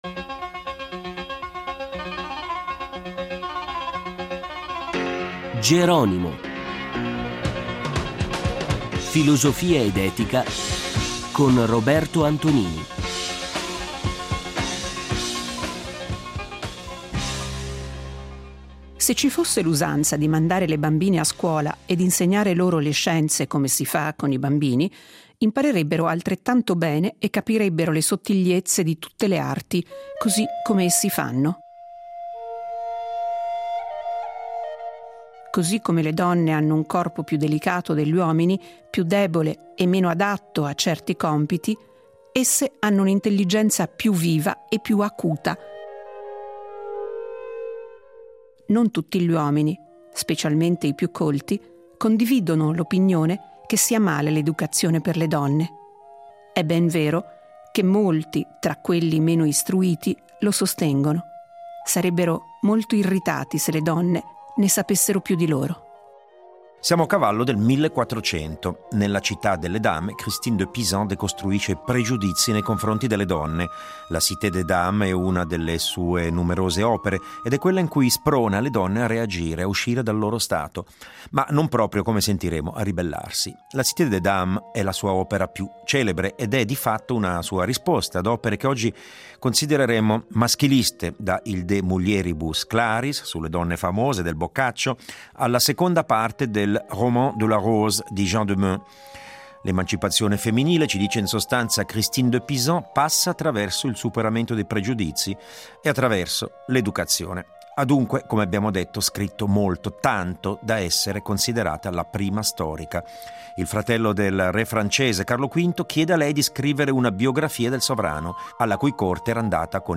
Festival del Medioevo di Gubbio